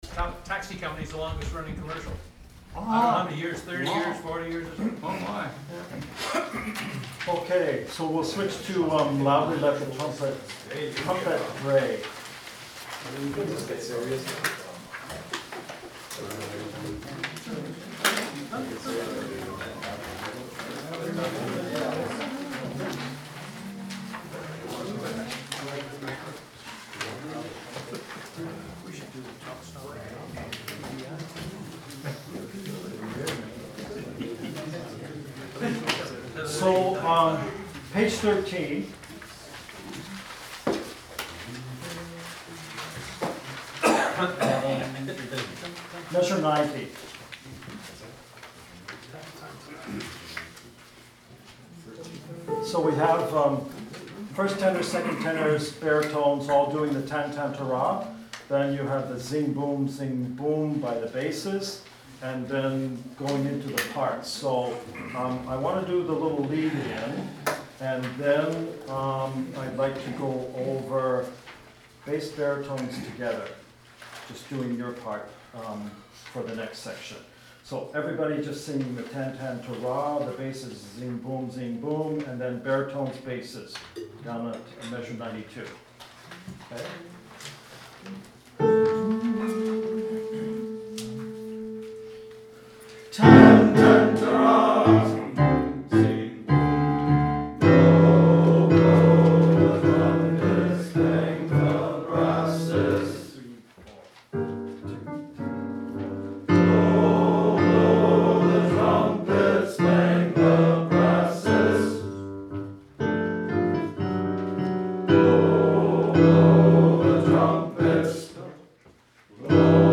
2.-Loudly-Let-The-Trumpet-Bray-Arp-26-18.mp3 — Coastal Voices Men's Choir
Rehearsal Files